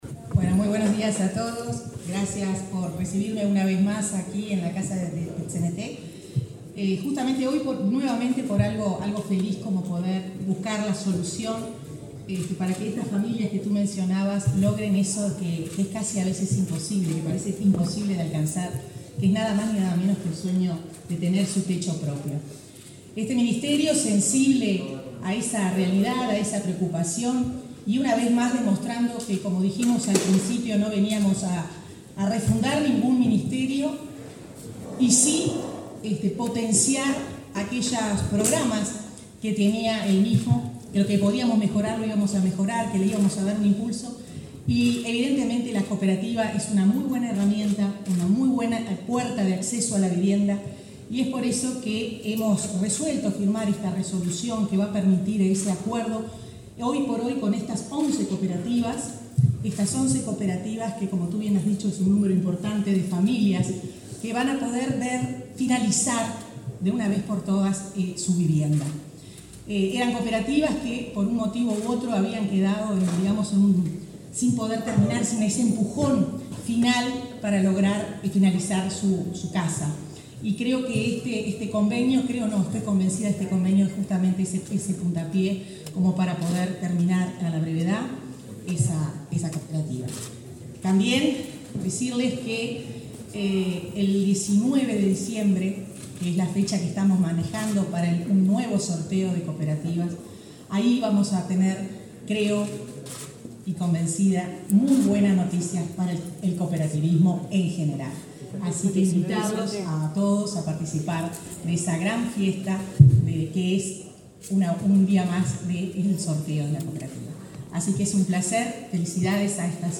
Palabras de la ministra de Vivienda, Irene Moreira
Este martes 22 en la sede del PIT-CNT, la ministra de Vivienda, Irene Moreira, firmó convenios con varias cooperativas para la reanudación de obras.